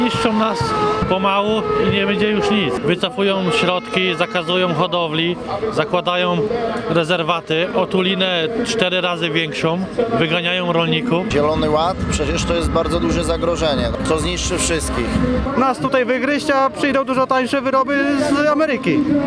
W piątek przed Europejskim Centrum Solidarności w Gdańsku protest przeciwko Europejskiemu Zielonemu Ładowi zorganizowali związkowcy "Solidarności".
Protestujący związkowcy wyrażali swój zdecydowany sprzeciw wobec kierunku, w jakim zmierza polityka Brukseli i polskiego rządu: